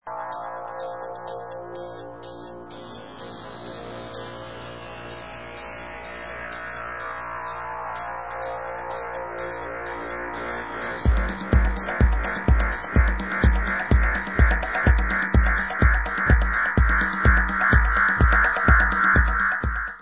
sledovat novinky v kategorii Dance
Dance/Electronic